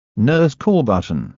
5 NurseCallButton
5-NurseCallButton.mp3